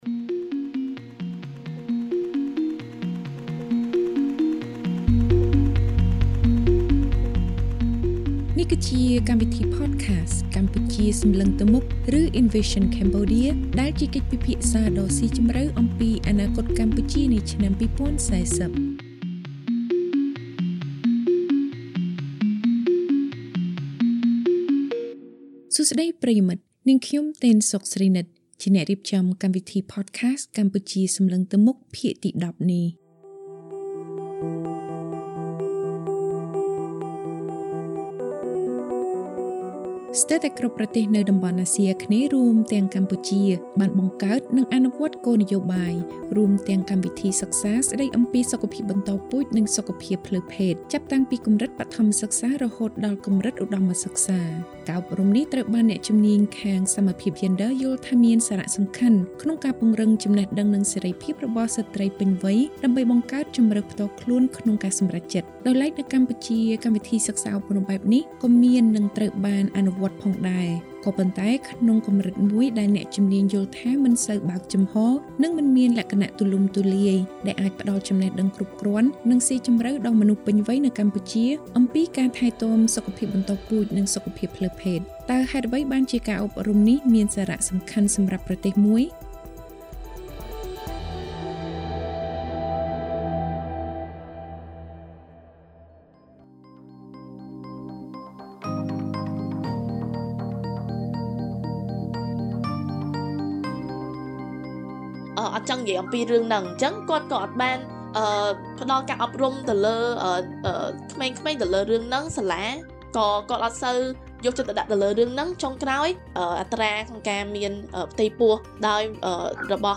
នៅក្នុងភាគទី ១០ នេះ លោកអ្នកនាងស្វែងយល់ដឹងអំពីសារៈសំខាន់នៃការអប់រំស្តីអំពីសុខភាពបន្តពូជនិងផ្លូវភេទដែលបើកចំហនិងស៊ីជម្រៅ តាមរយៈកិច្ចពិភាក្សា